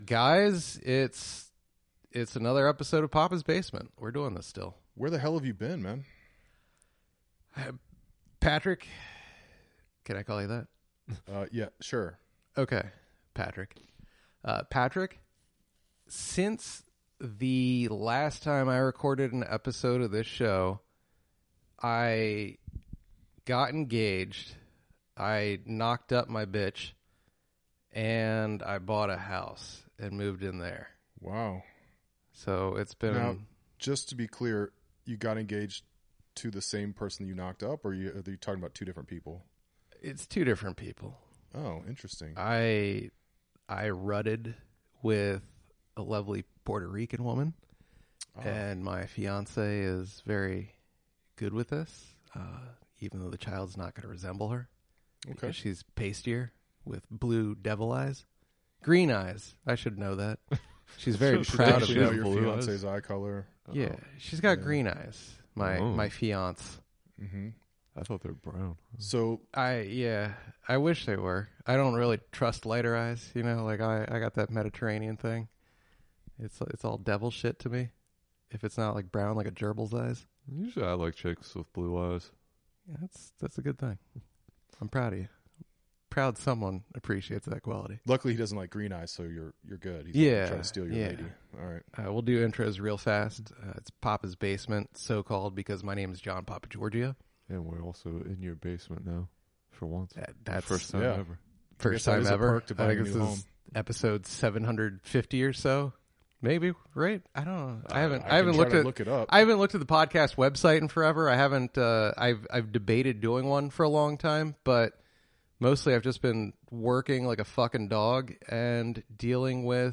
DC's Premier Comedy Podcast and Radio Show.